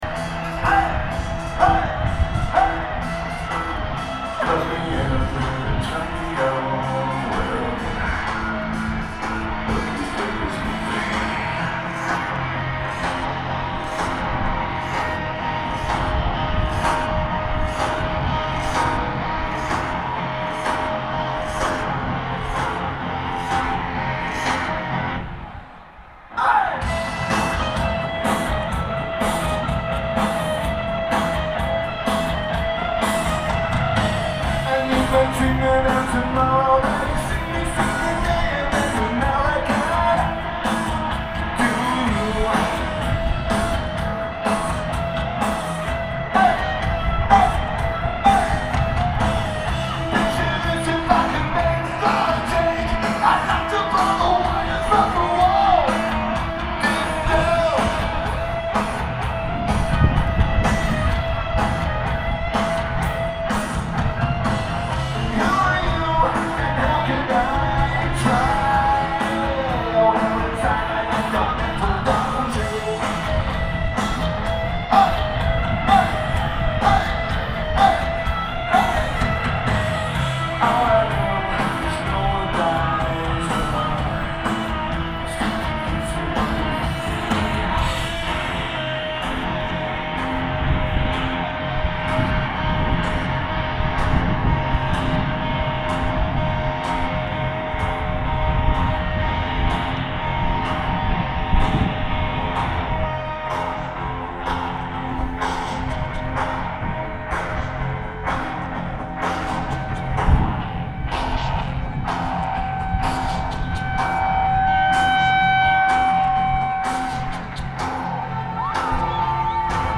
Lineage: Audio - AUD (Sharp MD SR60 + Sony ECM-DS70P)